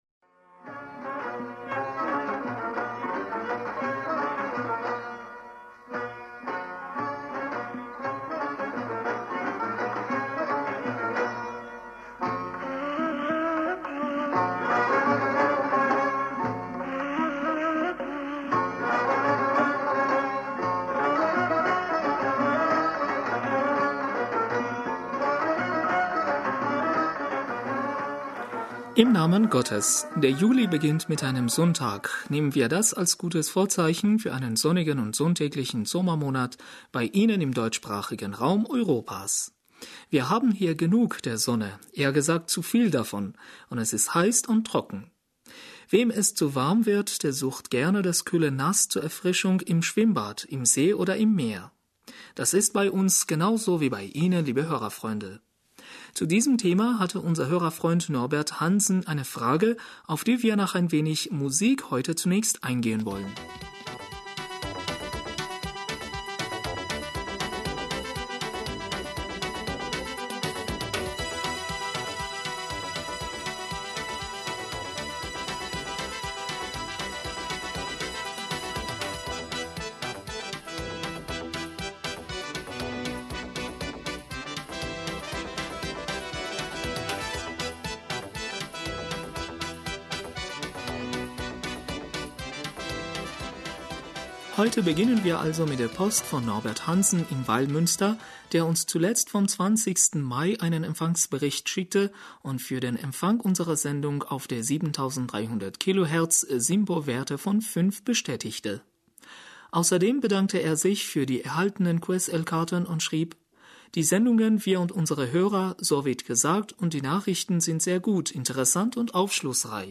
Hörerpostsendung am 01. Juli 2018 - Bismillaher rahmaner rahim - Der Juli beginnt mit einem Sonntag – nehmen wir das als gutes Vorzeichen für eine...